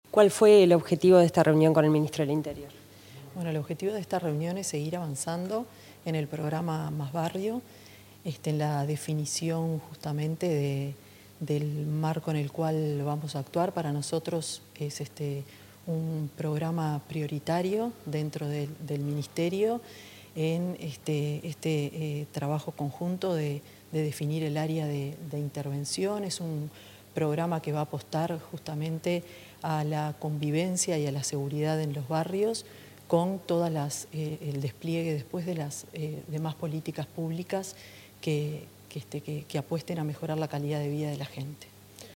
Declaraciones de la ministra de Vivienda, Tamara Paseyro
La ministra de Vivienda, Tamara Paseyro, dialogó con la prensa tras una reunión entre las carteras de Interior y Vivienda, relacionada con los avances